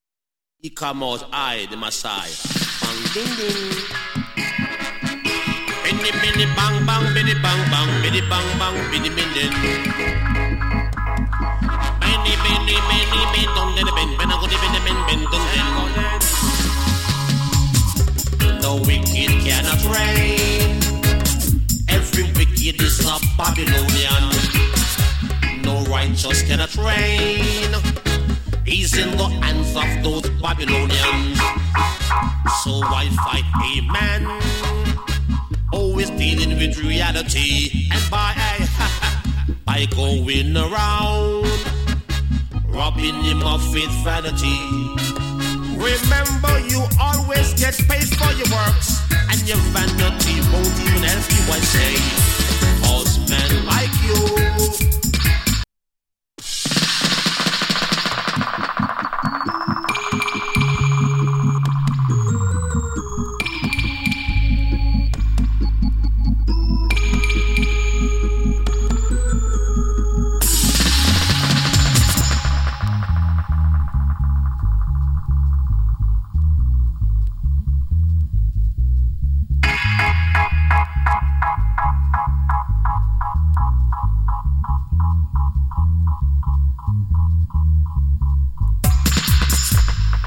STEPPER DIGITAL ROOTS !! ＋ DUB.